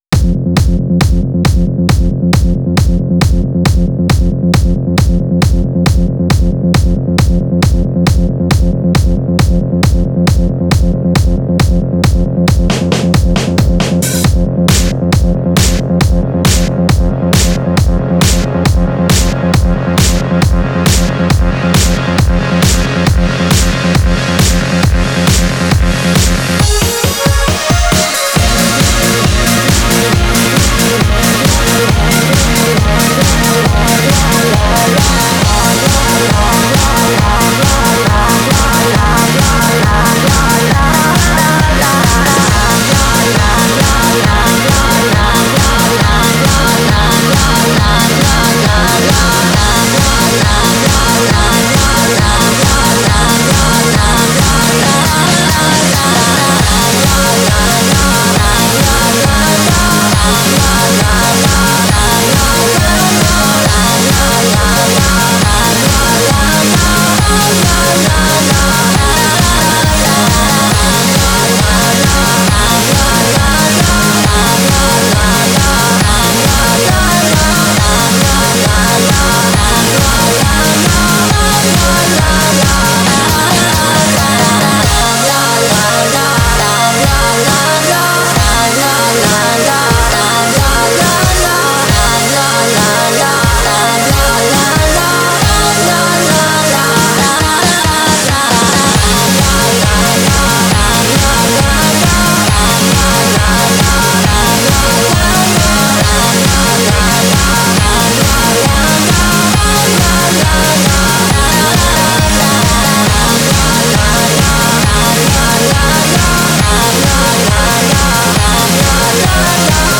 Genre(s): Electro-Rock